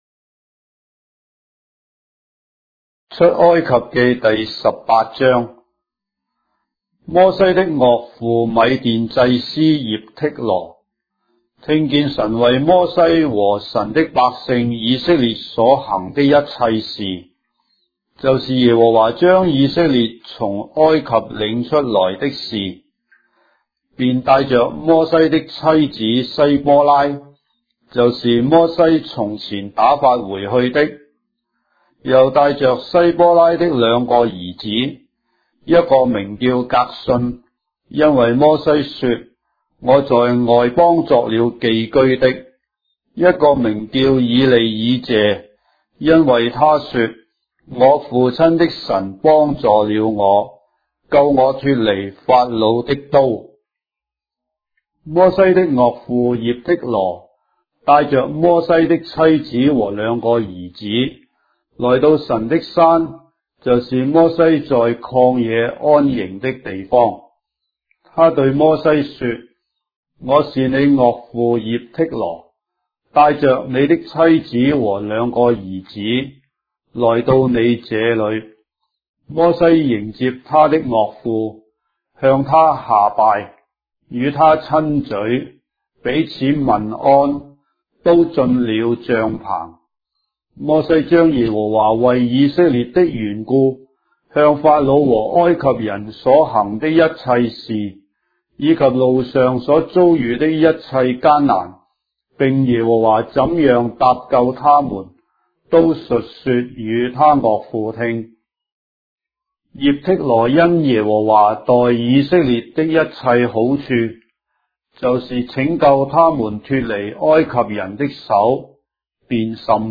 章的聖經在中國的語言，音頻旁白- Exodus, chapter 18 of the Holy Bible in Traditional Chinese